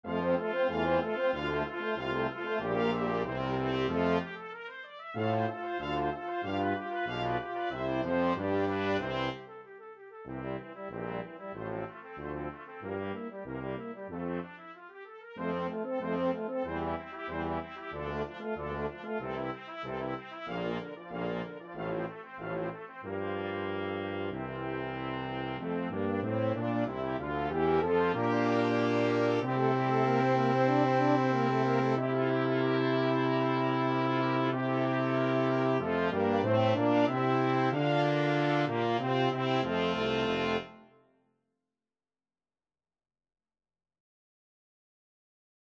Free Sheet music for Brass Quintet
Trumpet 1Trumpet 2French HornTromboneTuba
4/4 (View more 4/4 Music)
F major (Sounding Pitch) (View more F major Music for Brass Quintet )
Allegro =188 (View more music marked Allegro)
Brass Quintet  (View more Advanced Brass Quintet Music)
Classical (View more Classical Brass Quintet Music)